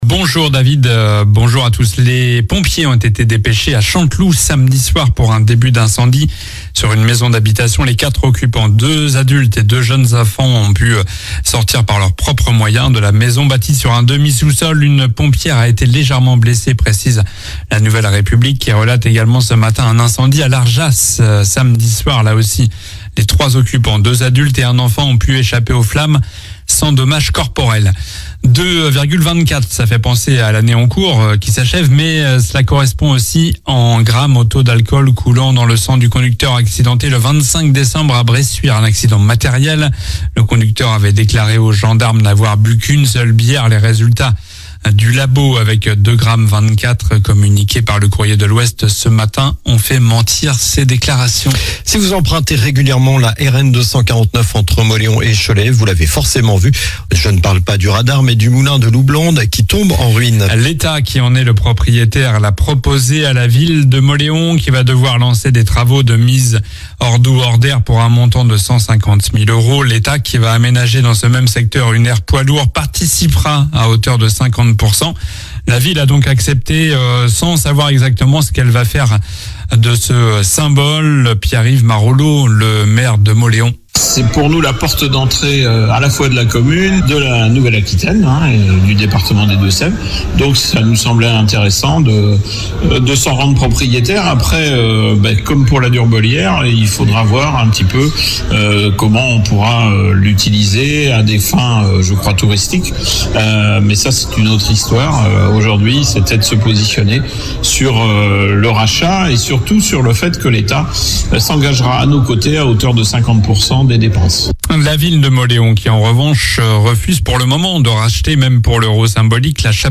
Journal du lundi 30 décembre (matin)